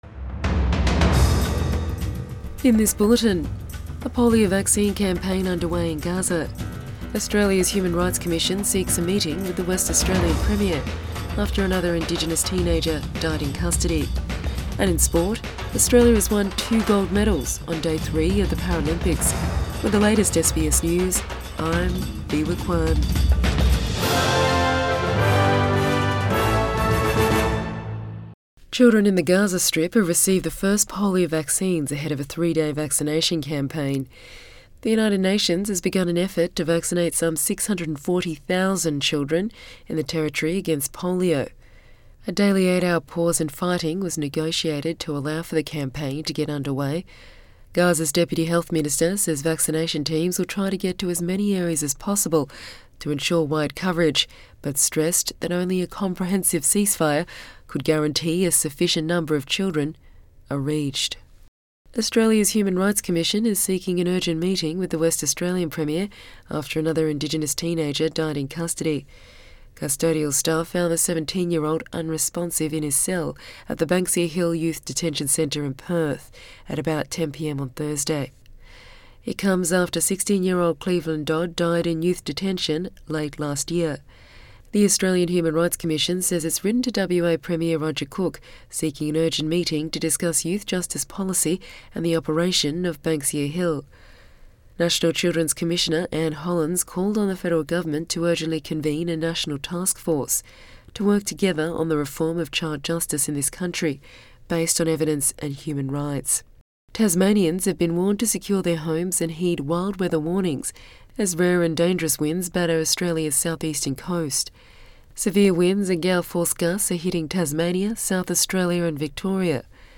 Morning News Bulletin 1 September 2024